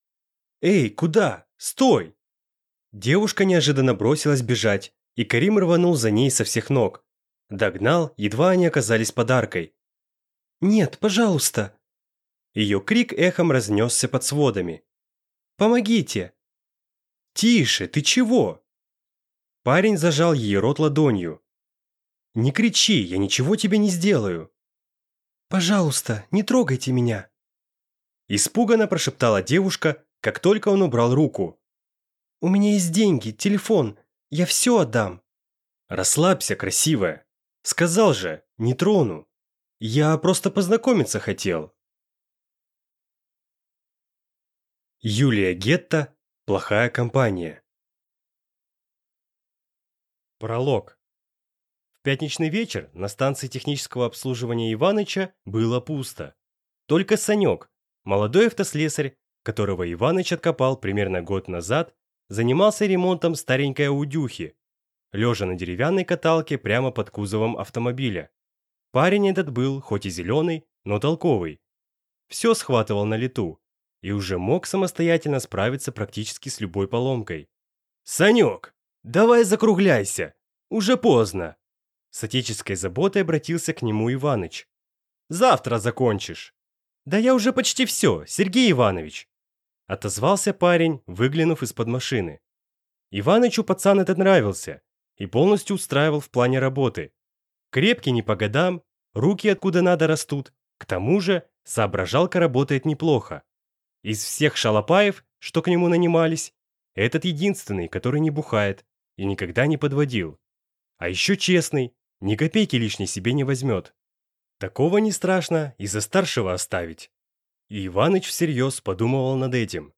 Aудиокнига Плохая компания